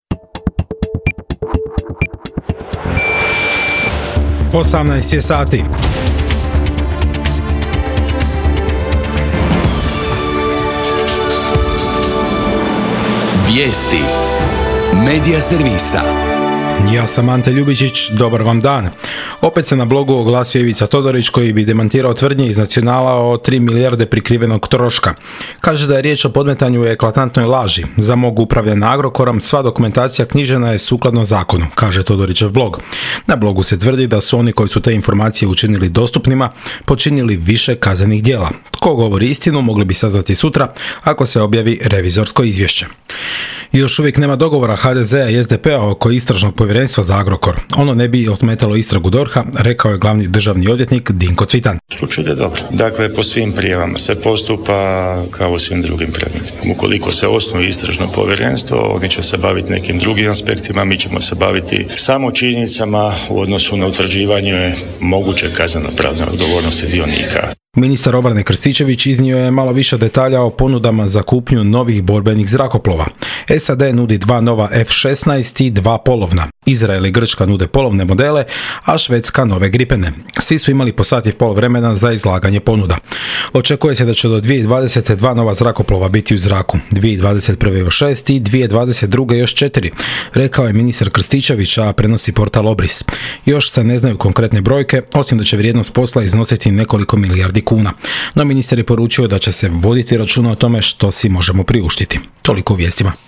VIJESTI U 18